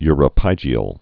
(yrə-pījē-əl, -pĭjē-)